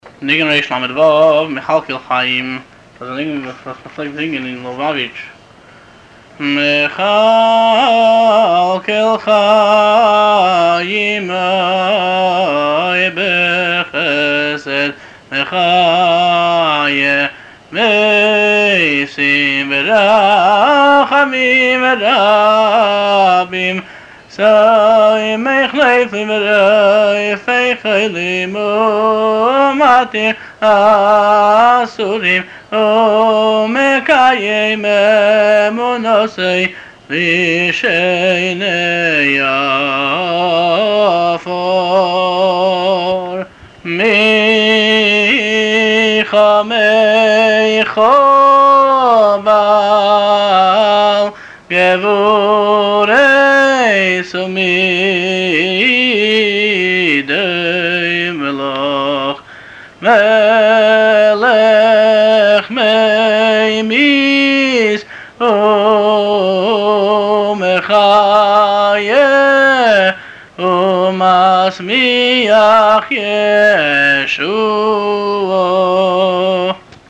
מכלכל חיים הינו ניגון המותאם למילים שבברכת "אתה גיבור" בשמונה עשרה, אותה היו חזני ליובאוויטש שרים בחזרת הש"ץ בימים נוראים.